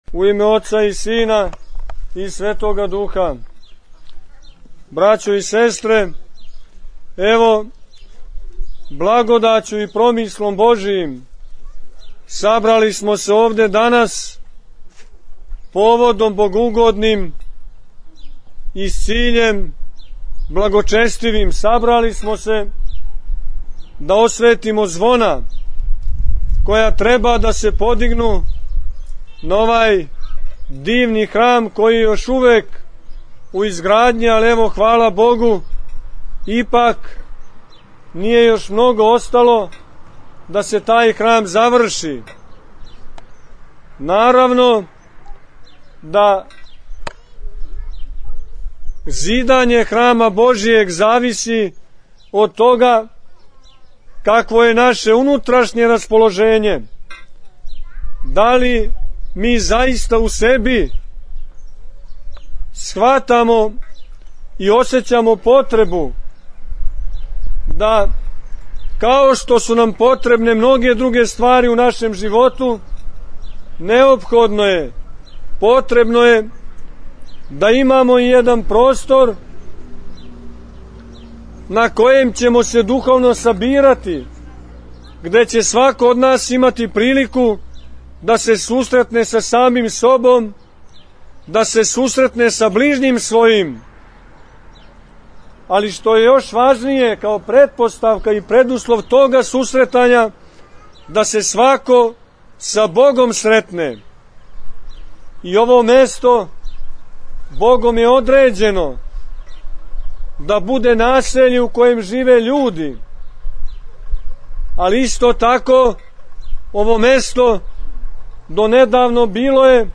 Уочи празника Светога пророка Илије, 1. авгута текуће године, извршено је освећење и подизање звона на звоник храма Пресвете Тројице у Чонопљи. По благослову Његовог Преосвештенства Епископа бачког Господина др Иринеја, чин освећења је извршио Епископ јегарски Господин др Порфирије, уз саслужење свештенства архијерејског намесништва сомборског и мноштва верног народа.